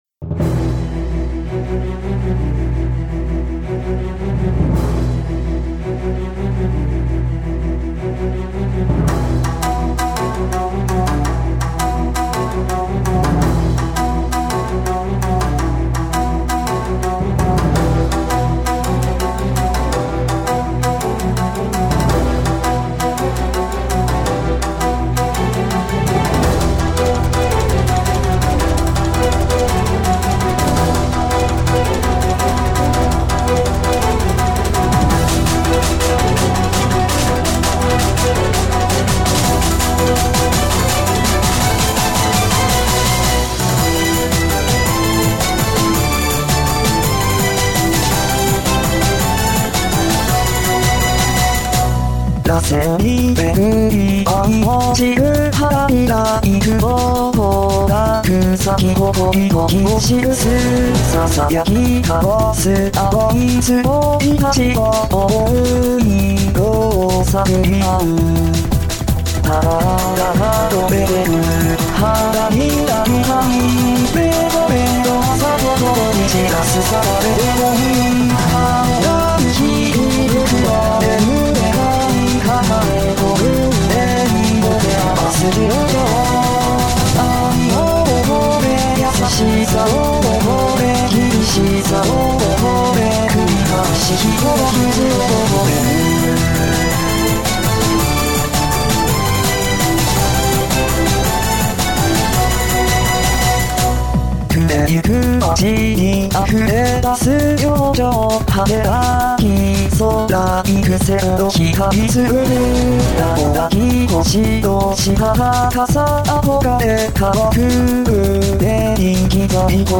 自分的には明るく録ったつもりだが・・・
発声が若干空気抜けてない程度になったくらい・・・